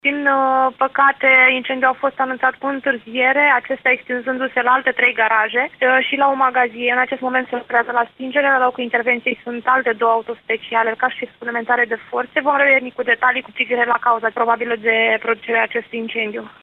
Într-o declarație acordată postului nostru de radio